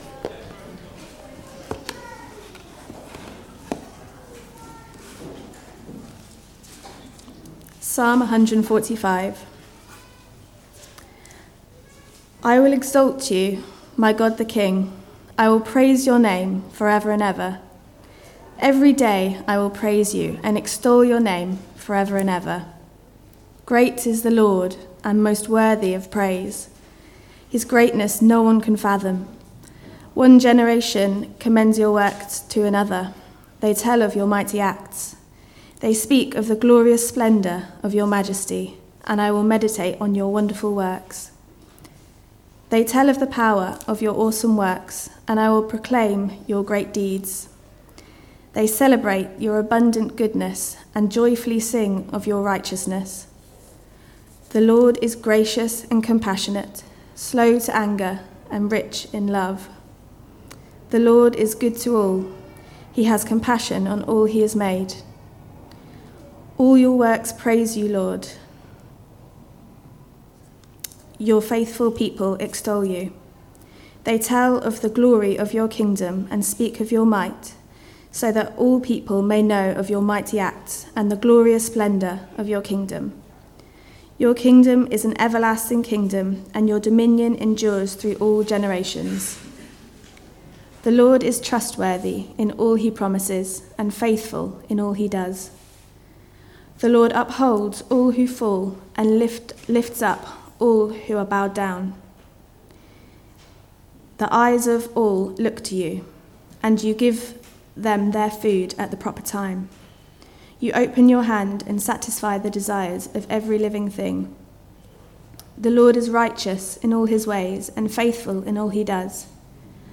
Psalm 145 Service Type: Weekly Service at 4pm Topics